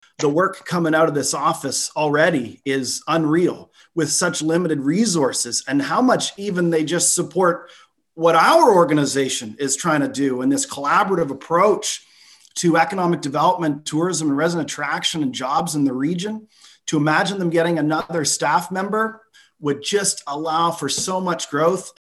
Belleville's Economic and Destination Development Committee meets virtually, on February 25, 2021.